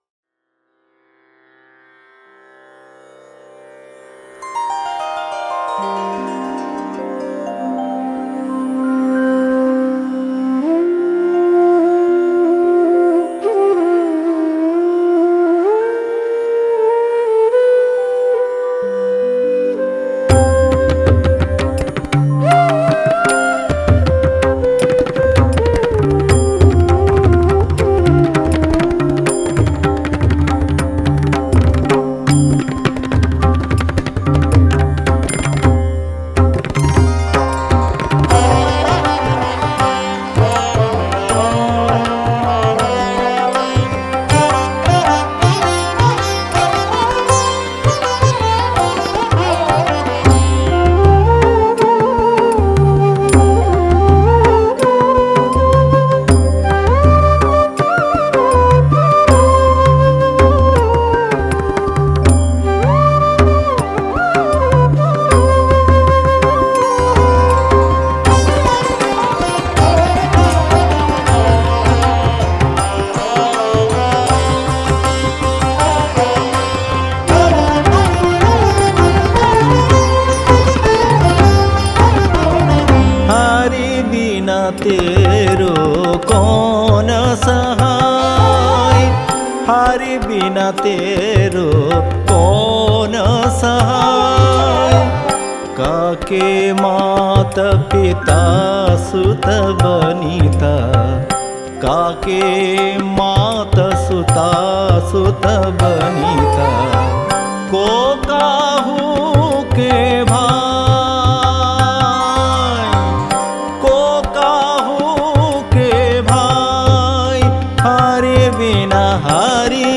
Bhajans